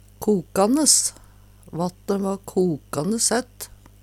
kokanes - Numedalsmål (en-US)